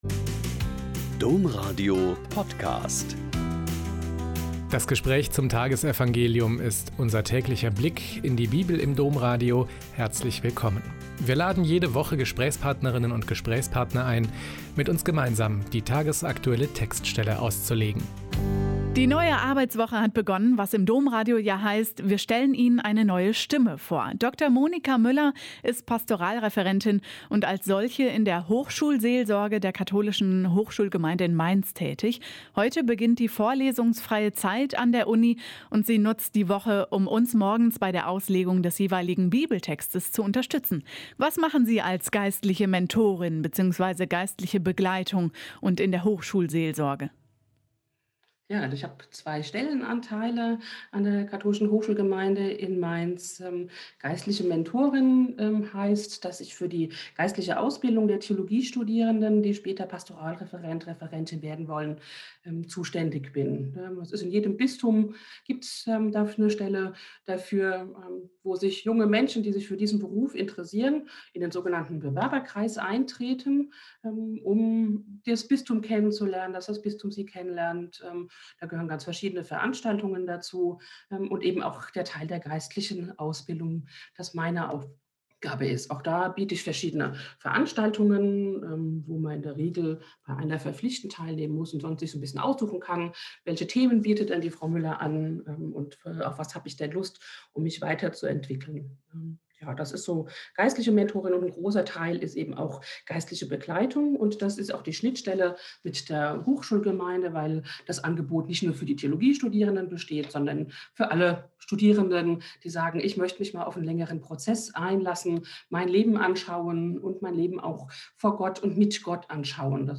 Mk 6,53-56 - Gespräch